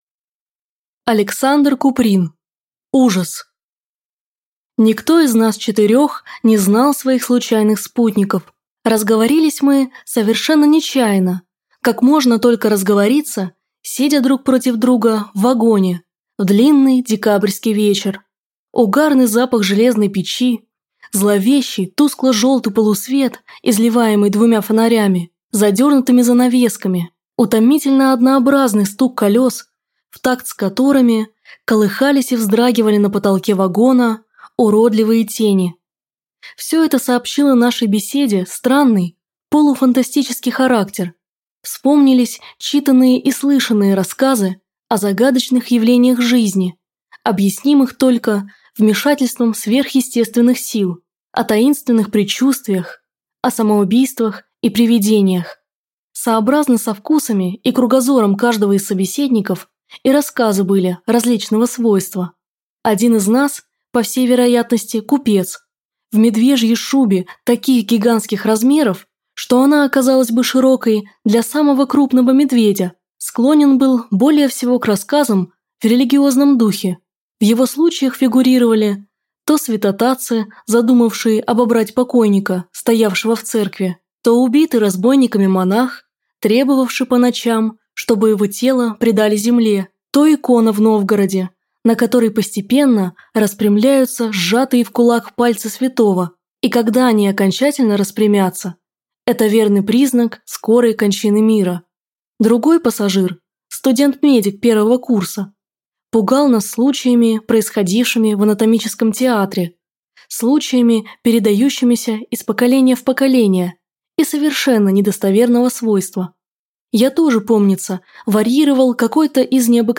Аудиокнига Ужас | Библиотека аудиокниг
Прослушать и бесплатно скачать фрагмент аудиокниги